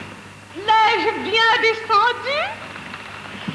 Lors de la première de la revue "Vive Paris", en 1933, Cécile Sorel, dans le rôle de Célimène, après avoir descendu avec succès le grand escalier, apostropha Mistinguett, placée à l'avant-scène, avec la phrase devenue célèbre : "L'ai-je bien descendu ?" (pour écouter à nouveau la voix de Cécile Sorel*, cliquez sur son nom)